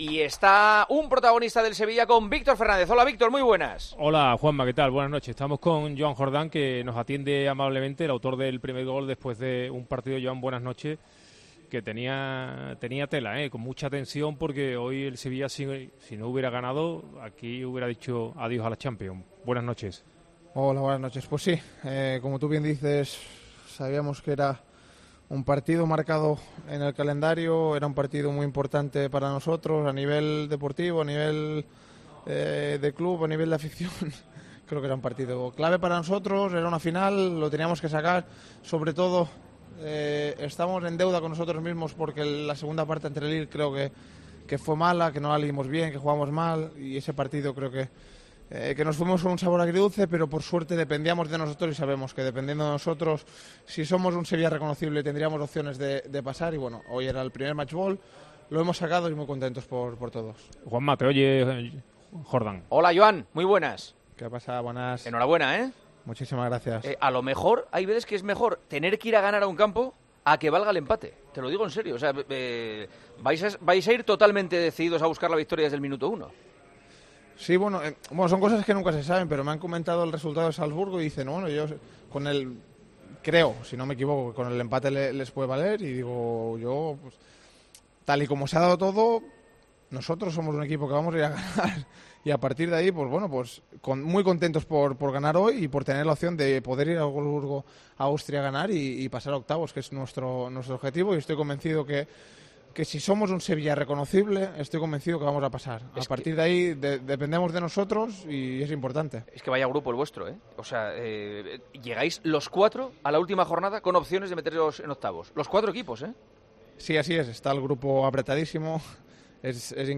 El futbolista del Sevilla ha estado en El Partidazo de COPE tras la victoria de su equipo ante el Wolfsburgo.